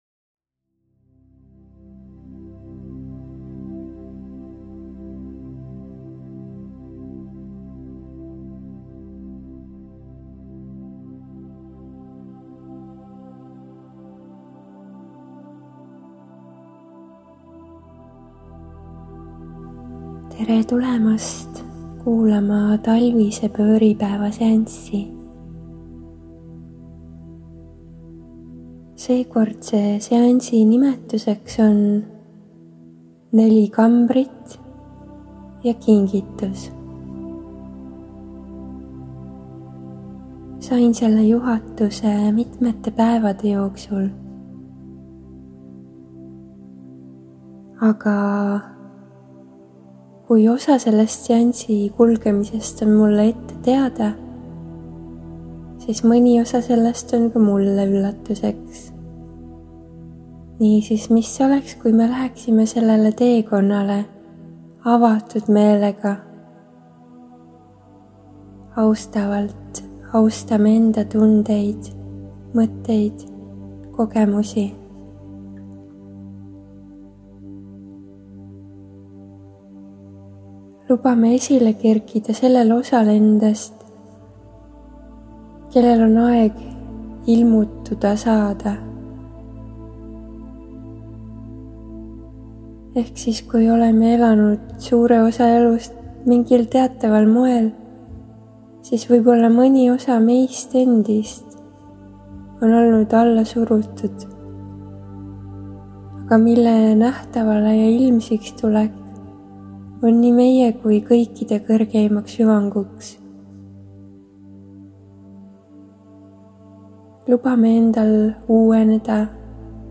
Kaugseanss-juhendatud meditatsioon on seekord kanaldsusseanss üldnimetusega NELI KRISTALLKAMBRIT JA KINGITUS.